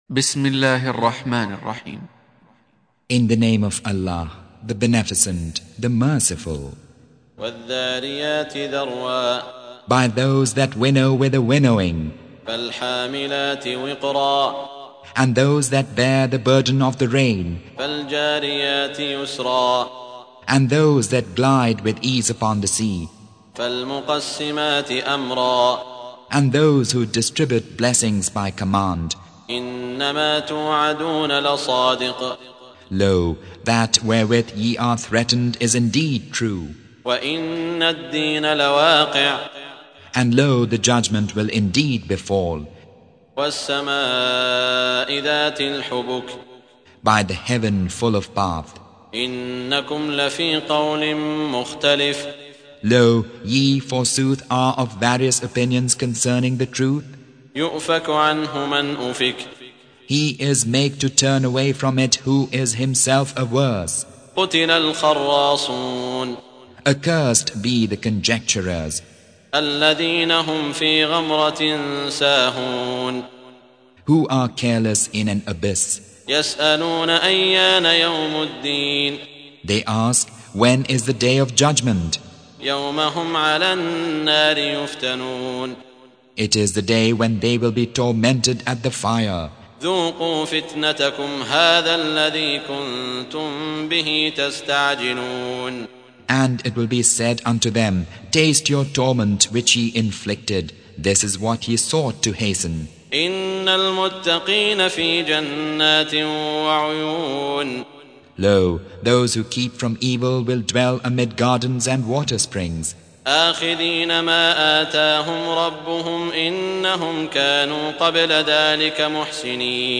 Surah Repeating تكرار السورة Download Surah حمّل السورة Reciting Mutarjamah Translation Audio for 51. Surah Az-Z�riy�t سورة الذاريات N.B *Surah Includes Al-Basmalah Reciters Sequents تتابع التلاوات Reciters Repeats تكرار التلاوات